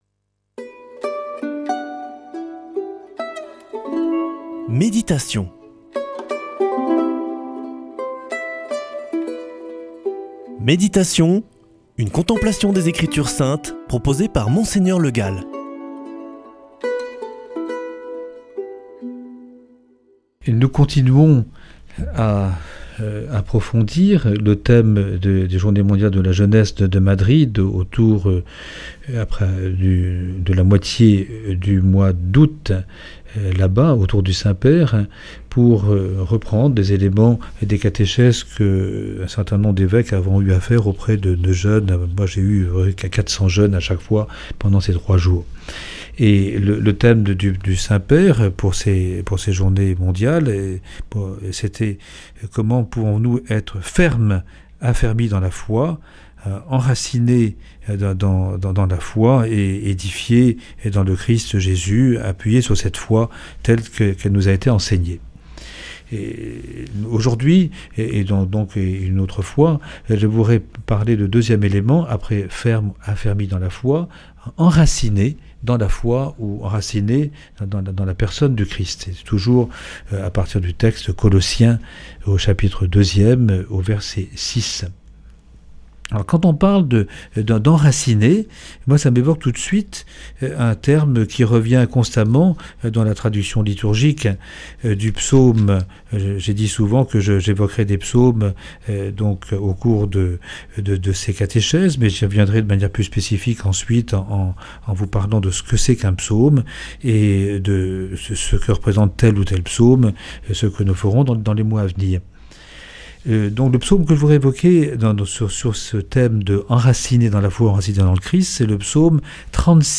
Méditation avec Mgr Le Gall
[ Rediffusion ]
Présentateur